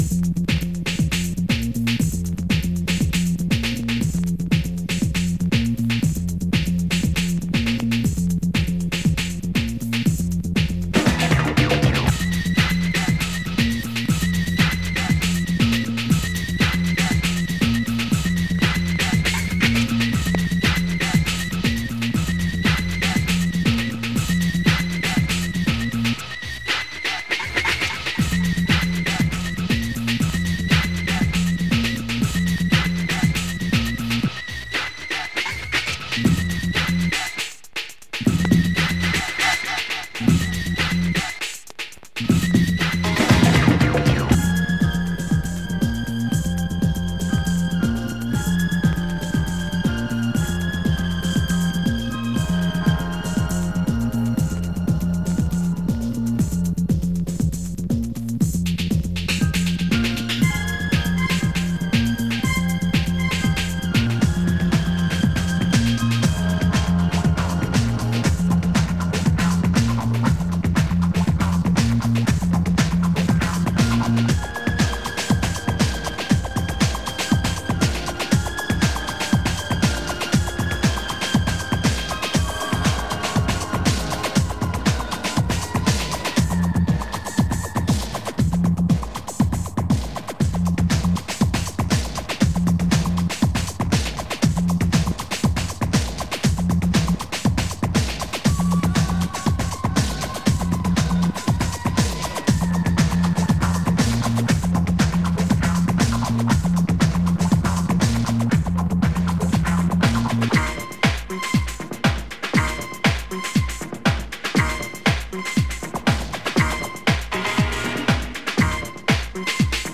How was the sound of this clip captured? Surface marks and scratches cause some crackles on playback